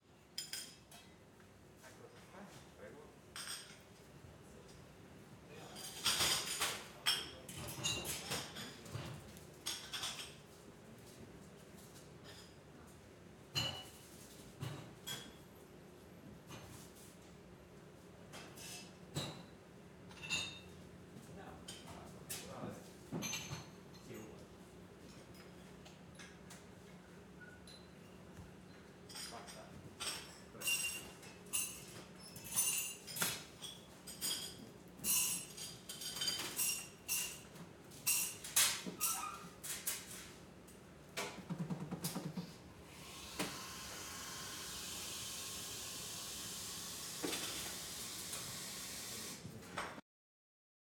coffeeshop.ogg